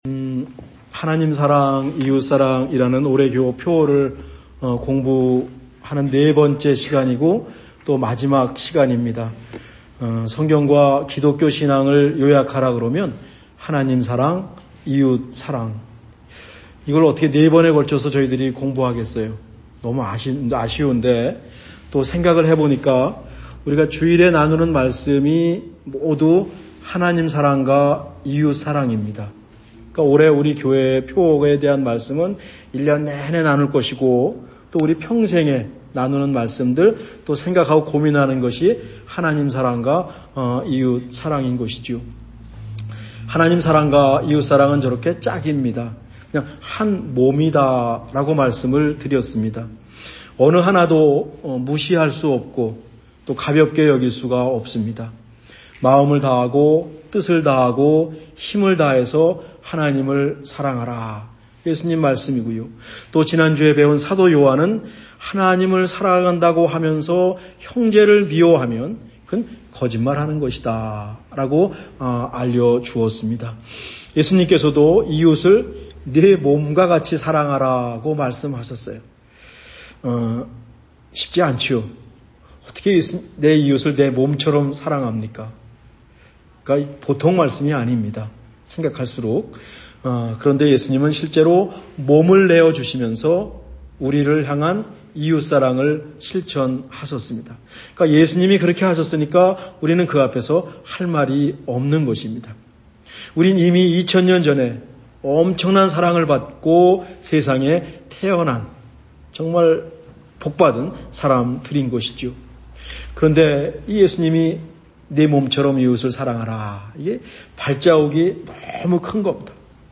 2026년 1월 4주 말씀